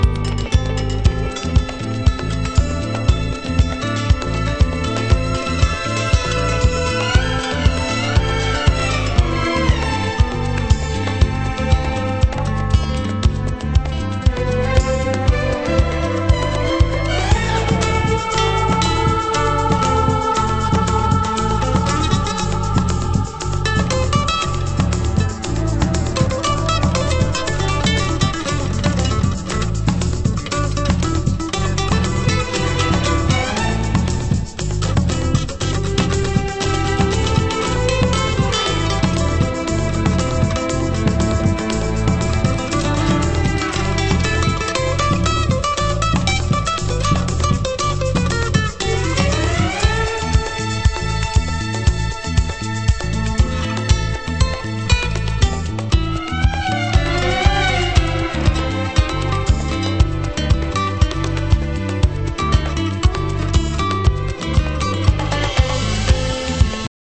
盤質：線の傷によるチリパチノイズ